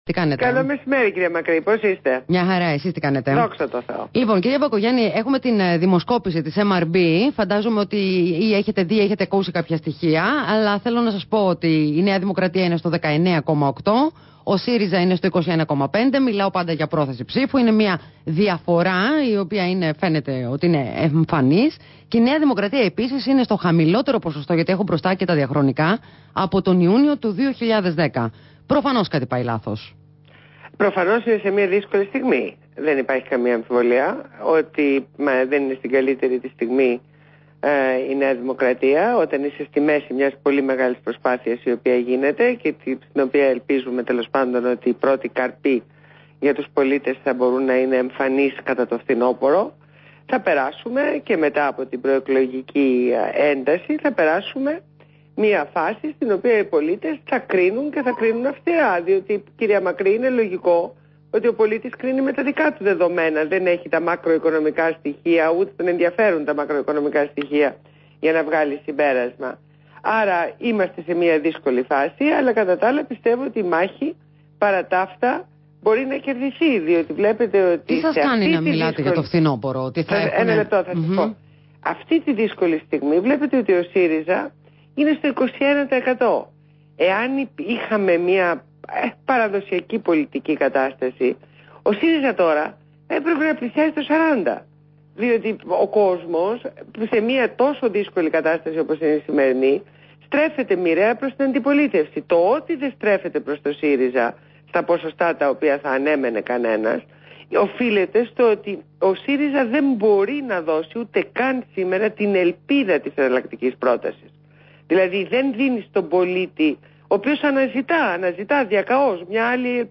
Συνέντευξη στο ραδιόφωνο REAL fm